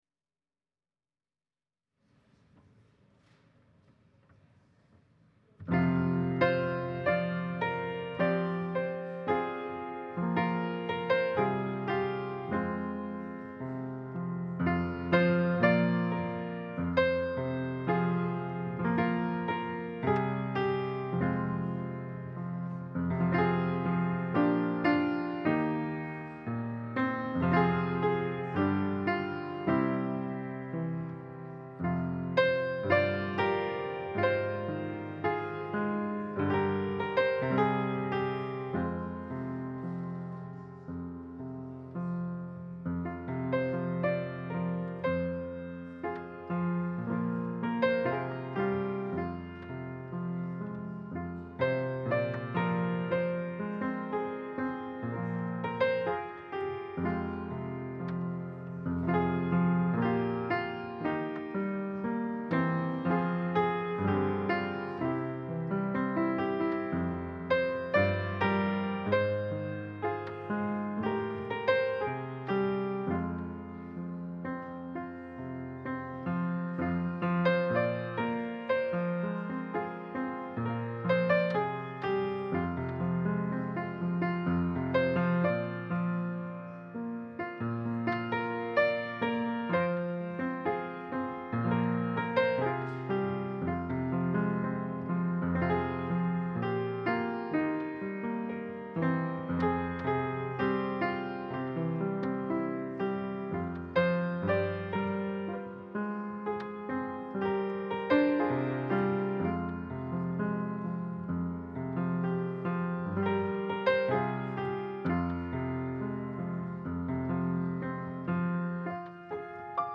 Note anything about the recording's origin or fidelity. Passage: Matthew 28: 16-20 Service Type: Sunday Service